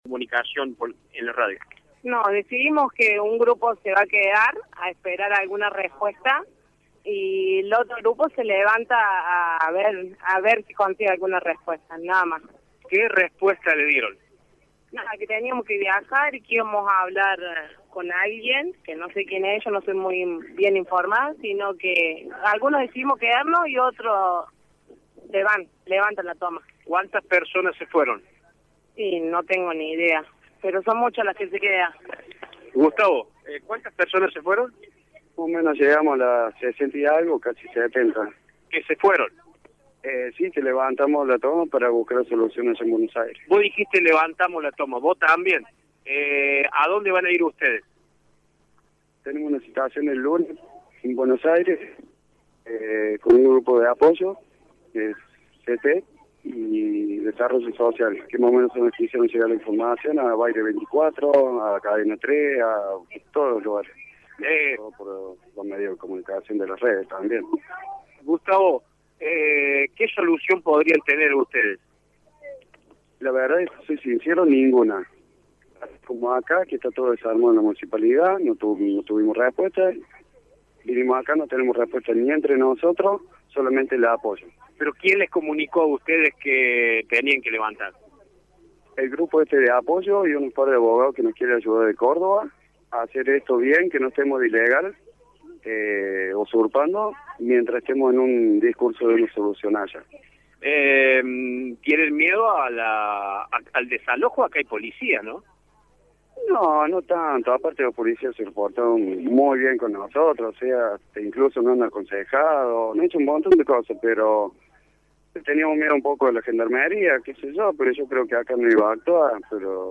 El móvil de Radio Show se llegó hasta la toma y pudo entrevistar a integrantes de los dos grupos.